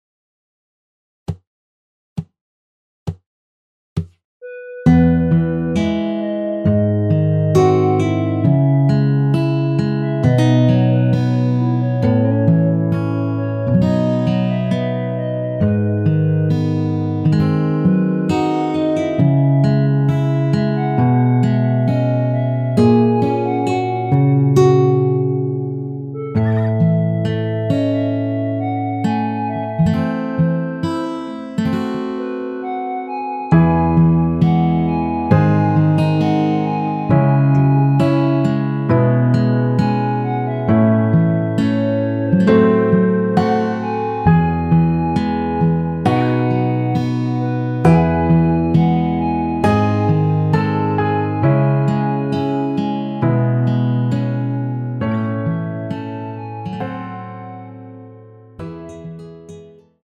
원키에서(-1)내린 멜로디 포함된 MR입니다.(미리듣기 확인)
앞부분30초, 뒷부분30초씩 편집해서 올려 드리고 있습니다.
중간에 음이 끈어지고 다시 나오는 이유는